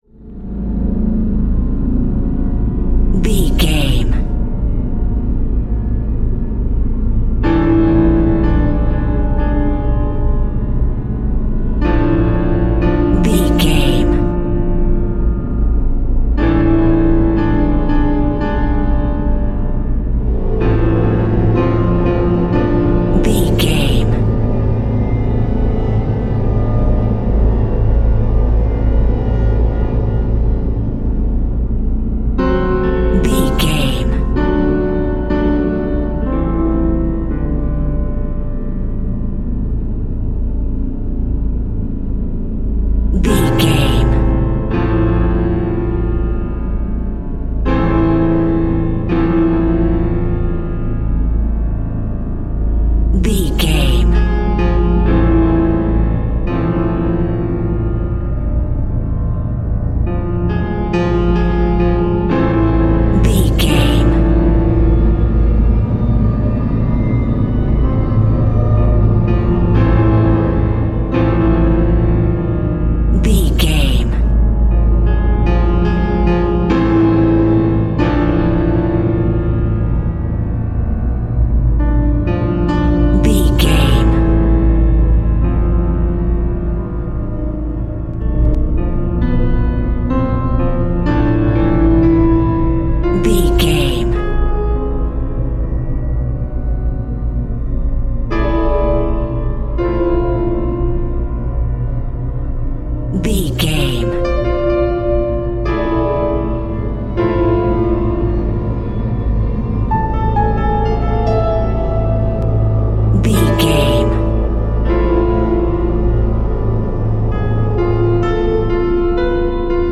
Scary Vampire Music.
Aeolian/Minor
tension
ominous
dark
suspense
haunting
eerie
piano
sythesizer
horror
Synth Pads
atmospheres